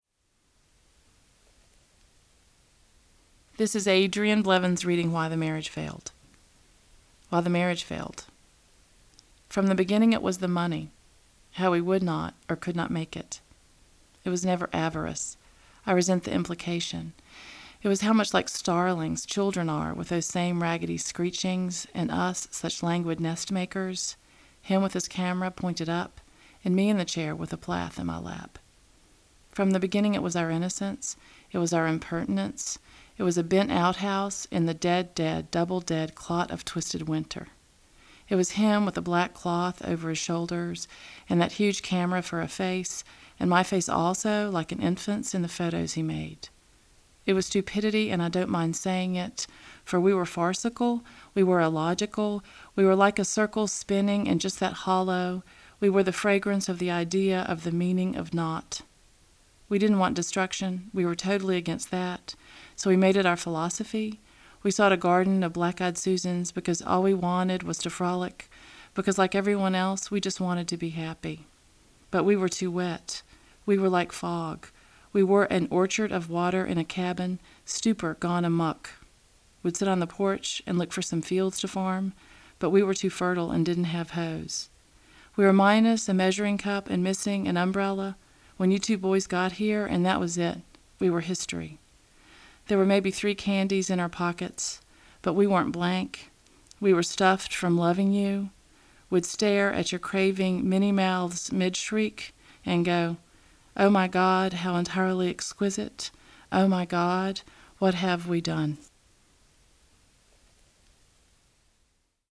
reads her poem